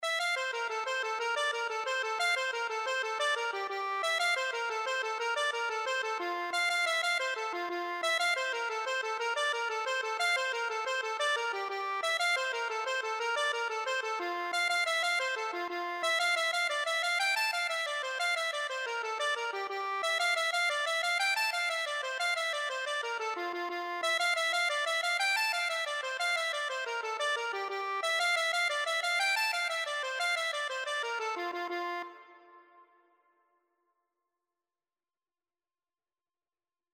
Accordion version
F major (Sounding Pitch) (View more F major Music for Accordion )
6/8 (View more 6/8 Music)
F5-A6
Accordion  (View more Intermediate Accordion Music)
Traditional (View more Traditional Accordion Music)